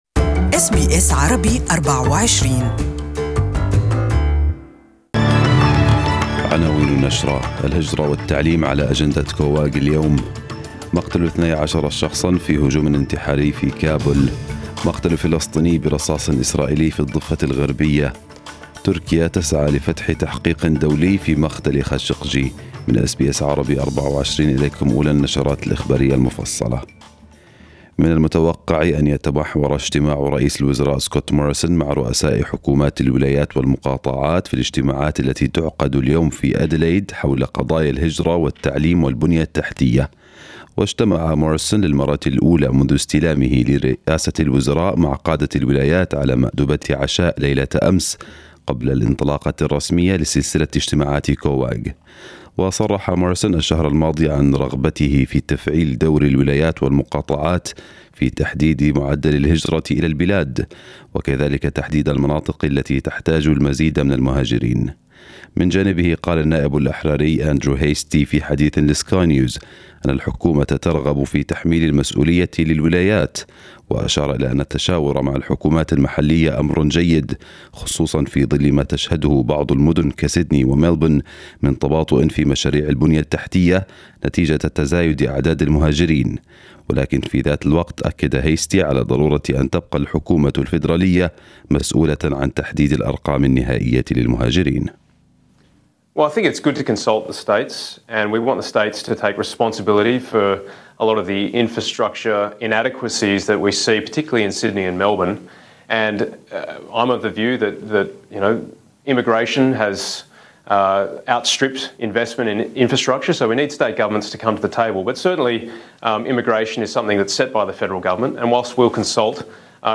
News bulletin of this morning